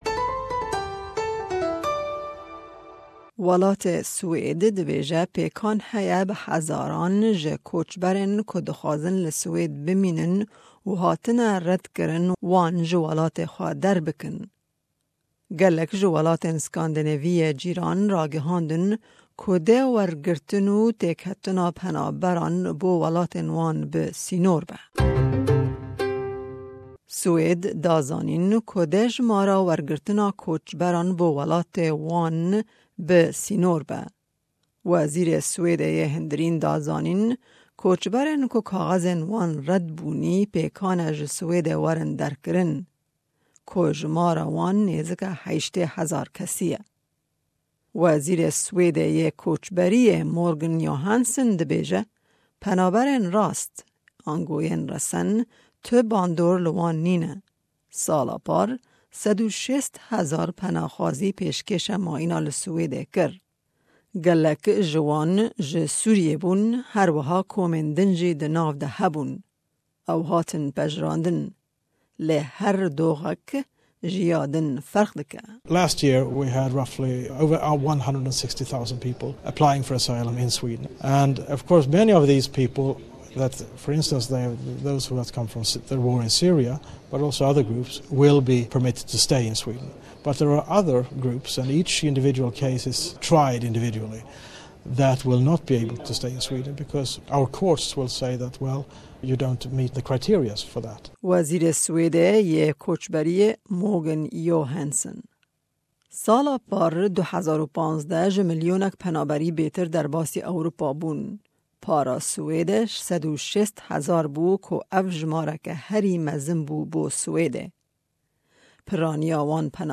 Pêkan heye ku welatê Swêdê ji destpêka sala 2017 de, penaxwazên ku kaxezên wan red bûyî ji welatê xwe derxînin. Ev raporta ji SBS bi zimanî Kurdî î Îngilîziye.